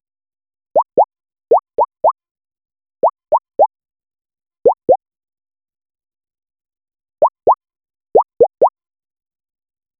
This sound needs a sharp, piercing high-frequency transient (PFFSSH) combined with a deep, gut-punching sub-bass kick (THUMP) that gives it immense weight and power. 0:10 Cartoon "pop-out" sound: soft leaf rustle followed by a cute whoop-hop pop. Short, crisp, playful. Duration ~0.15–0.25s. Bright, high-mid emphasis, light transient. No voices. 0:10 Single revolver gunshot, dry and isolated.
cartoon-pop-out-sound-sof-pgnawohx.wav